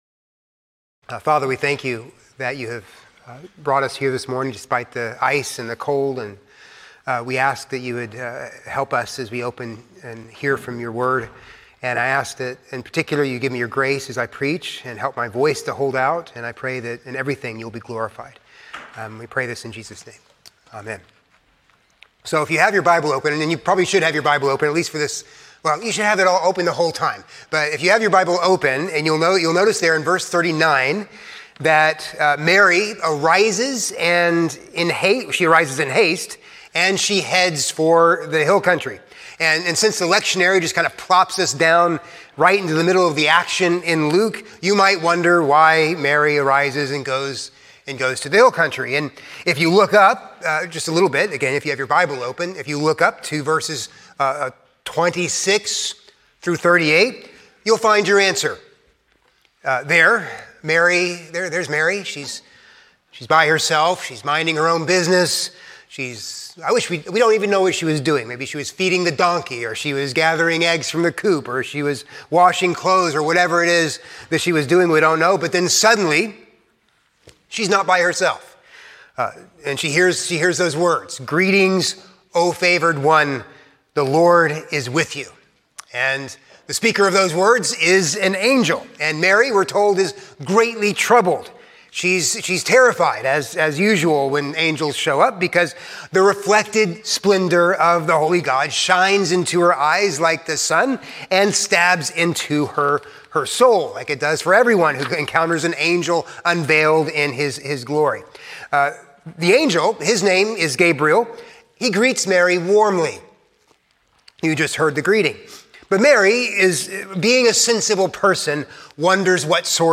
A sermon on Luke 1:39-56